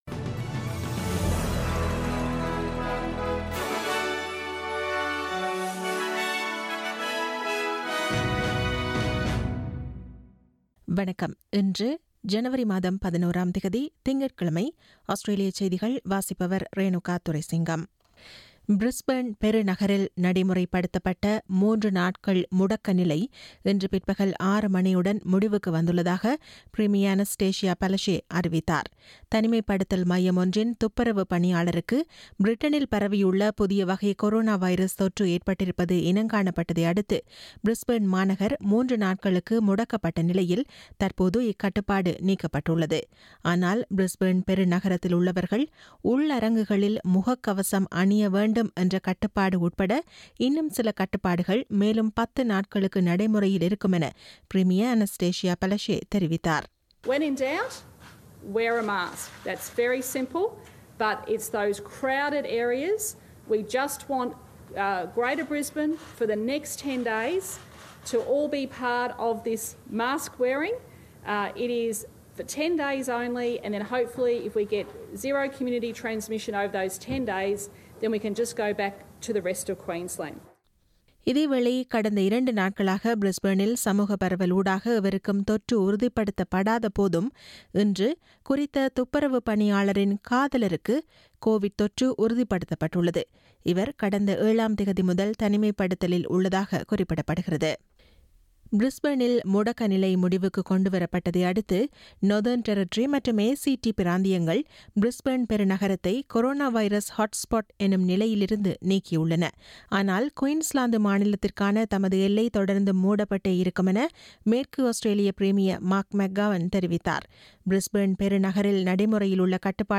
Australian news bulletin for Monday 11 January 2021.